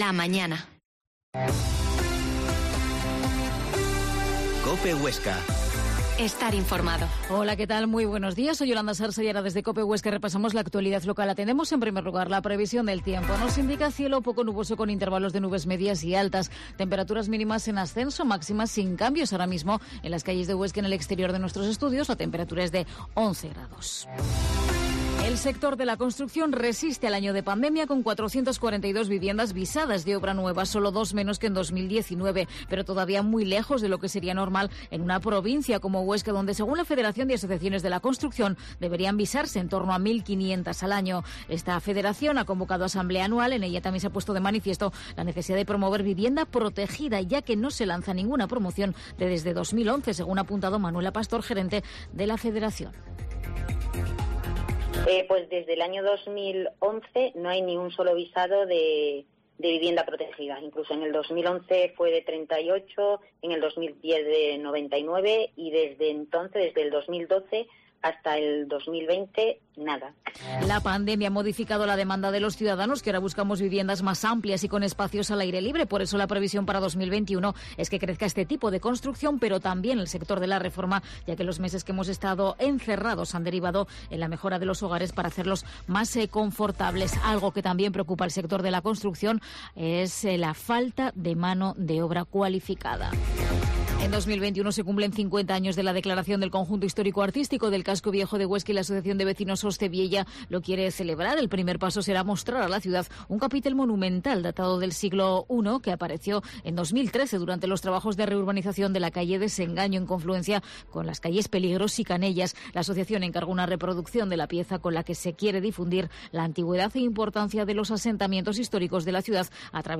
La Mañana en COPE Huesca - Informativo local Herrera en Cope Huesca 8,24h